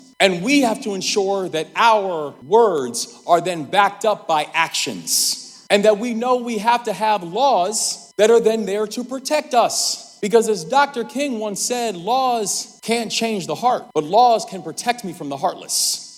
Maryland Governor Wes Moore was one of the main speakers this past weekend at the 2025 Human Rights Campaign annual dinner in Washington D.C. The Governor spoke on state efforts to preserve human rights for all citizens saying that Maryland has doubled funding for hate crime protection as well as expanding gender-affirming care.